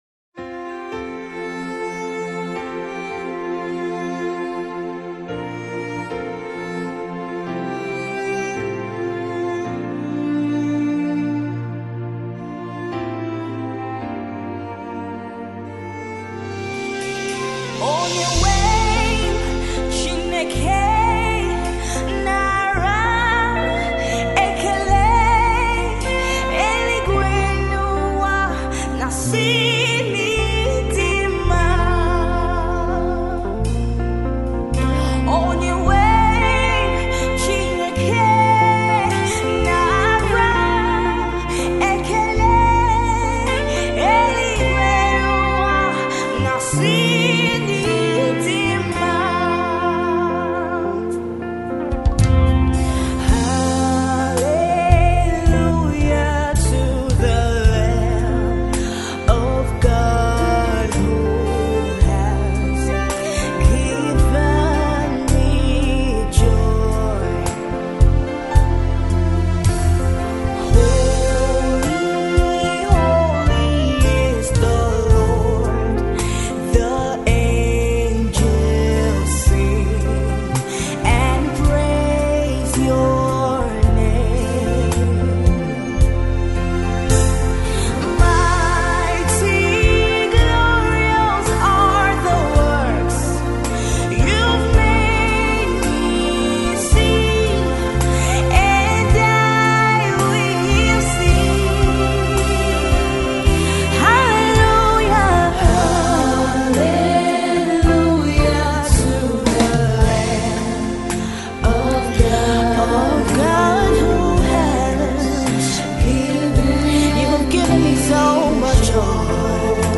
Gospel music
Her sound is unique, powerful, and anointed.